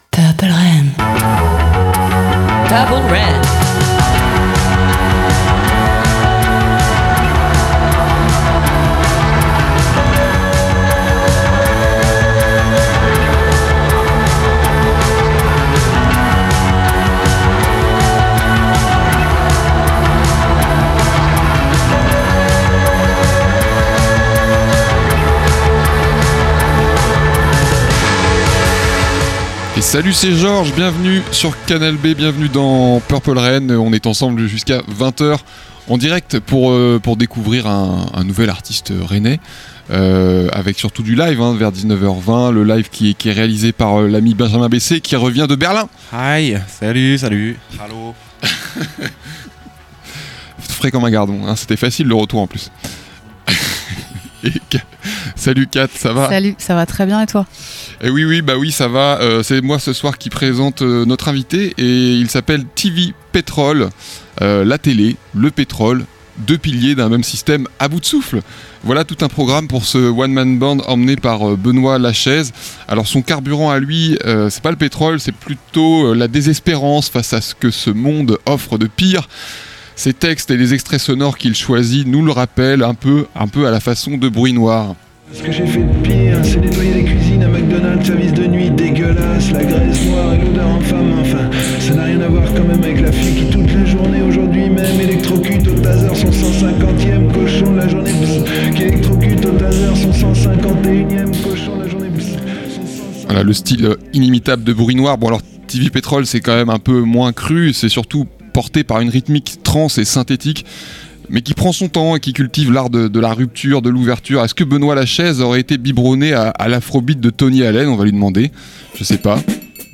LIVE de TV Petrol